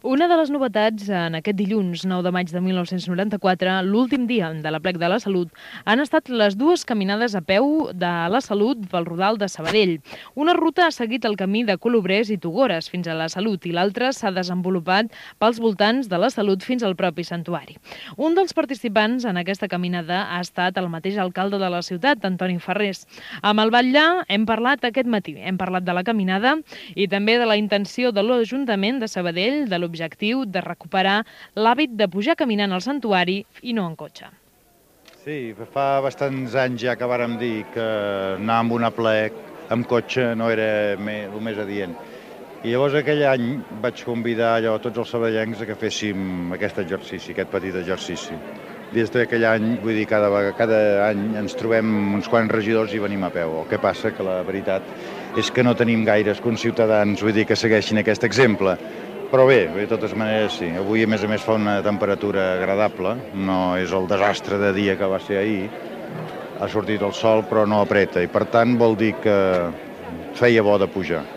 Conversa amb l'alcalde de Sabadell Antoni Farrés sobre la caminada amb motiu de l'Aplec de la Salut
Informatiu